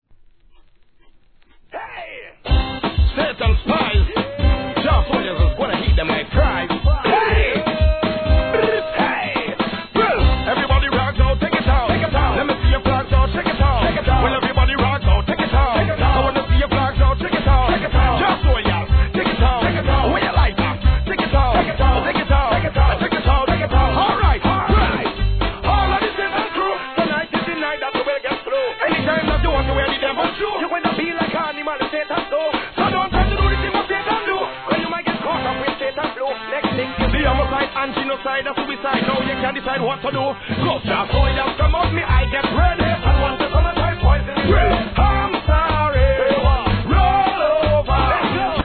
REGGAE
SOCA!!